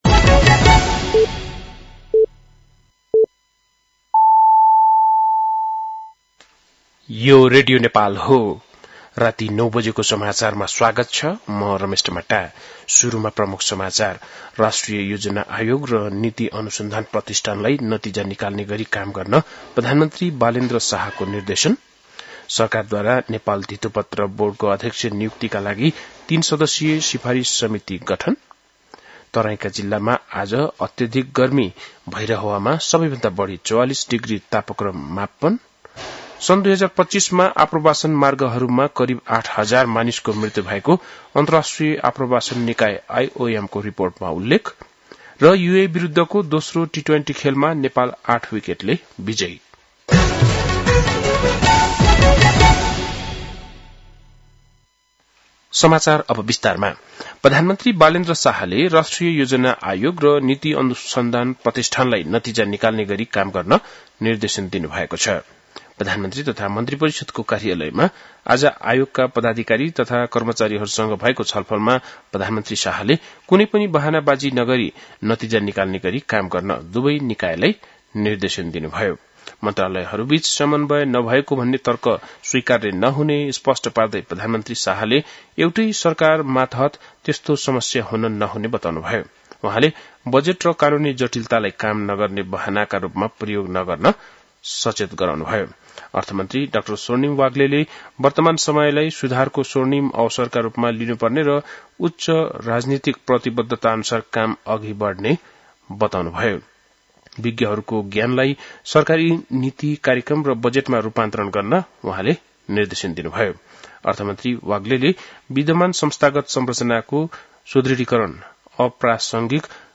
बेलुकी ९ बजेको नेपाली समाचार : ८ वैशाख , २०८३